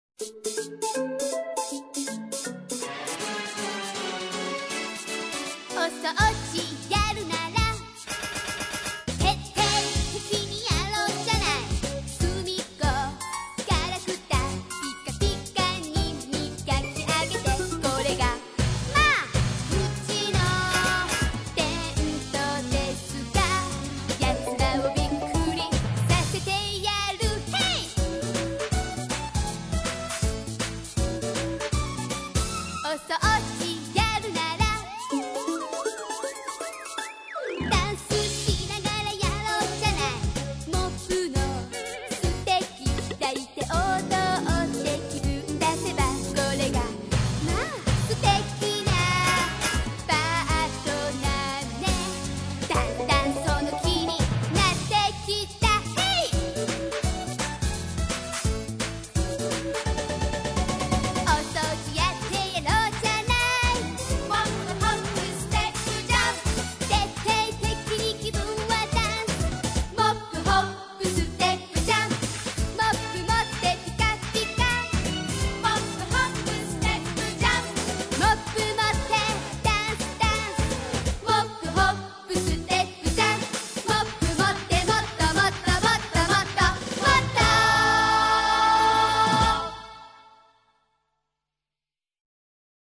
Песня Усаги